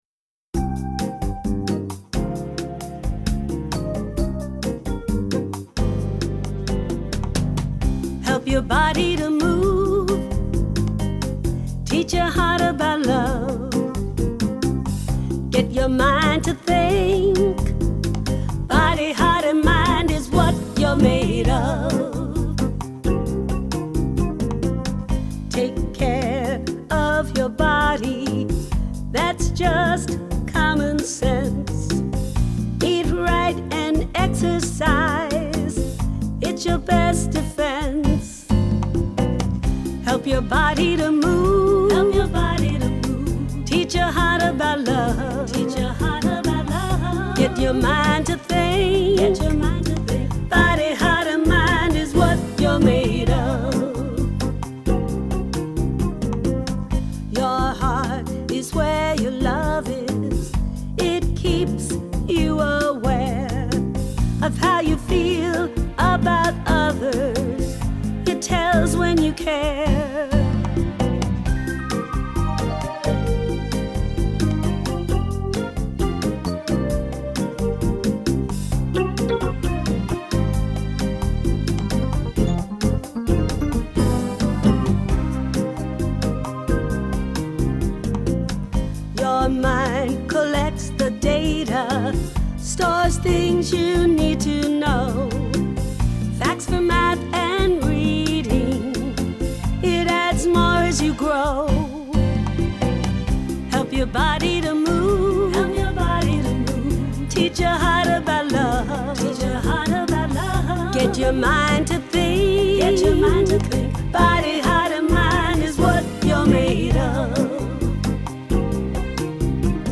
Genre: Childrens.